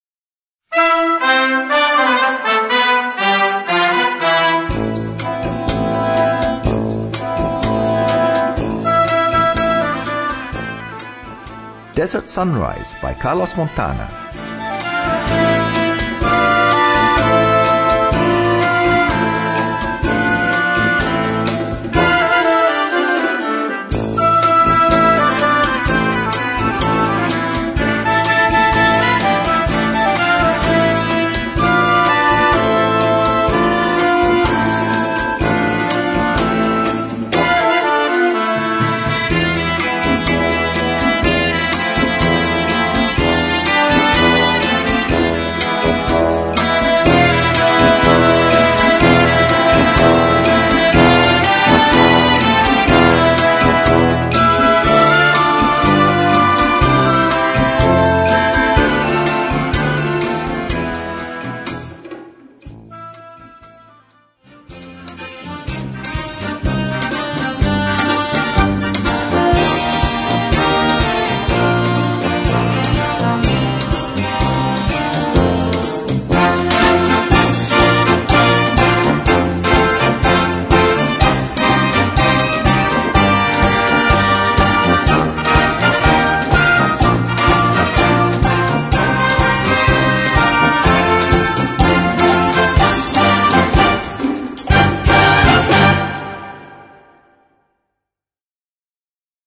Partitions pour orchestre d'harmonie et fanfare.